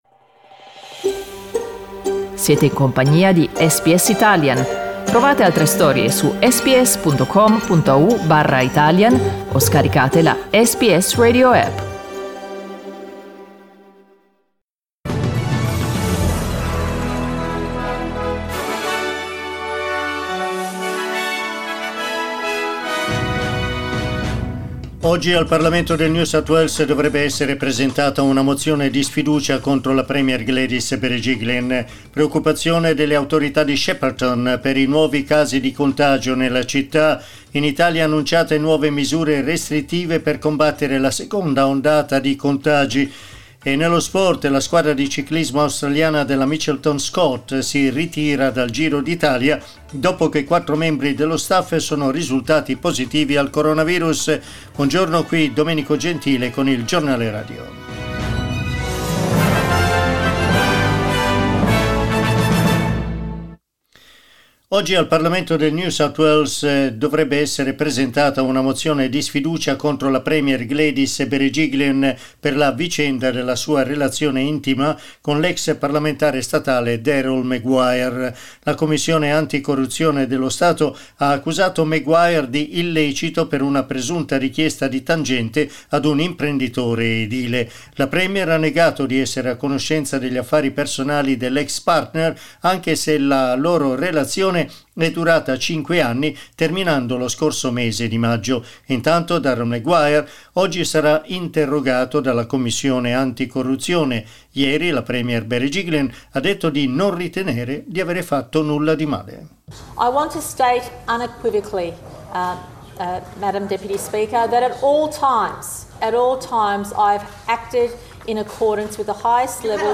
Our News bulletin broadcast at 9am.